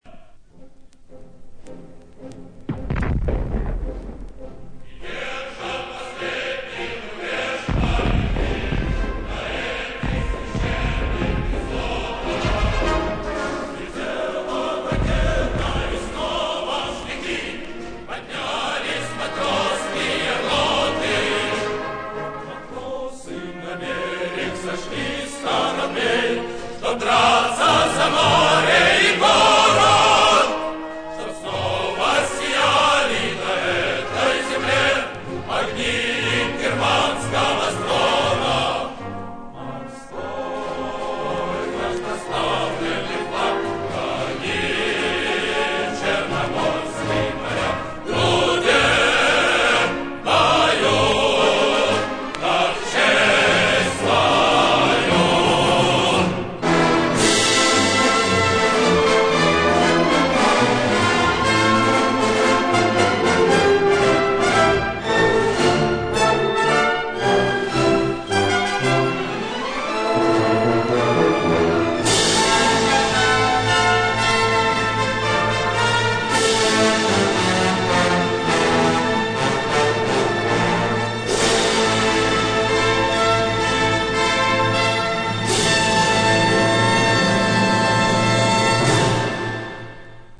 звук оп-ты 1961